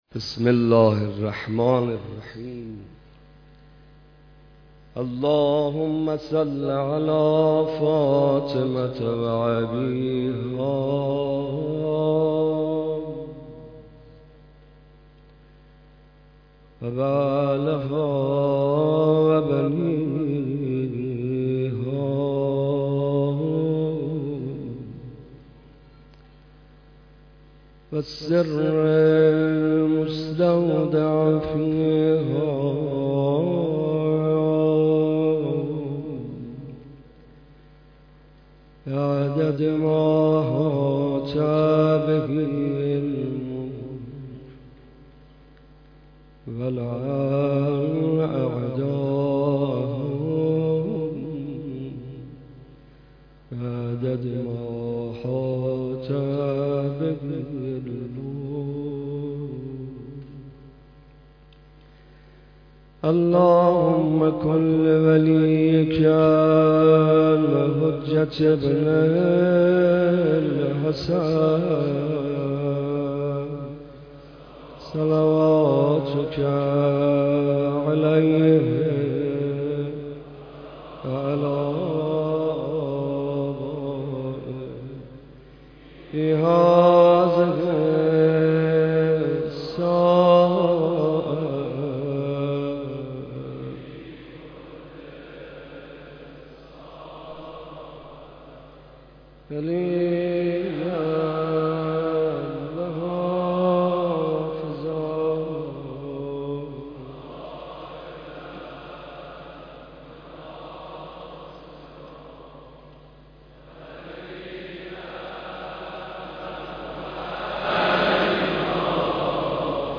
سخنرانی استاد صدیقی در مداحی حاج محمد رضا طاهری در بیت رهبری
دومین شب مراسم سوگواری دخت گرامی پیامبر اسلام، حضرت فاطمه زهرا سلام‌الله‌علیها با حضور حضرت آیت‌الله خامنه‌ای رهبر معظم انقلاب اسلامی در حسینیه امام خمینی رحمه‌الله برگزار شد. در این مراسم که اقشار مختلف مردم و جمعی از مسئولان حضور داشتند حجت الاسلام والمسلمین صدیقی سخنرانی و آقای محمدرضا طاهری نیز مرثیه سرایی کردند.